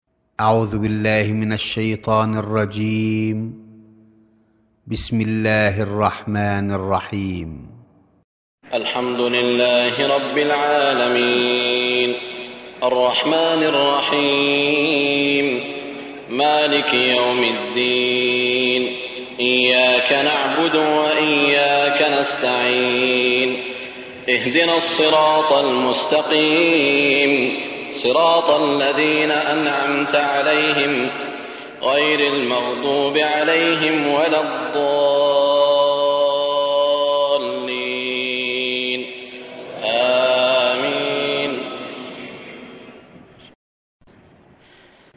récitation mp3 - Hafidh Abd er-Rahmân Sudais (qdlfm) - 438 ko ;
001-Surat_Al_Faatiha_(Prologue)_Cheikh_Sudais_Wa_Shuraym.mp3